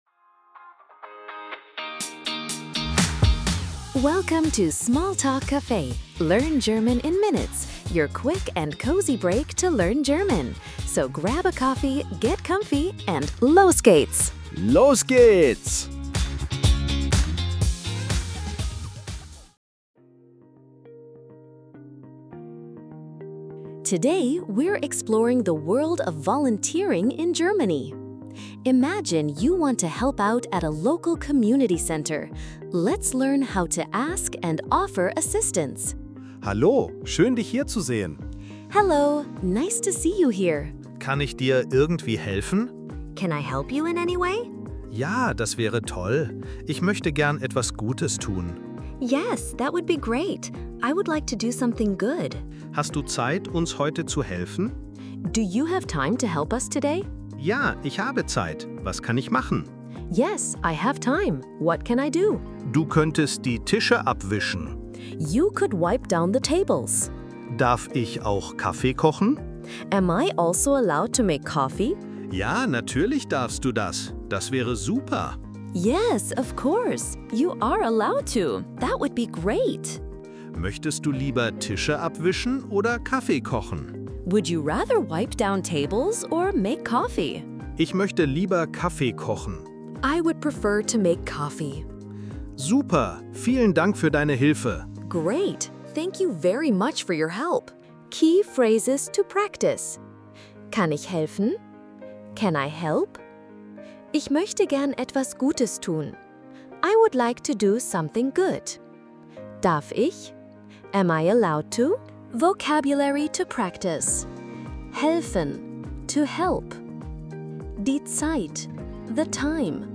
Tune in for quick, real-life dialogues, helpful tips, and the confidence boost you need to navigate daily errands in German!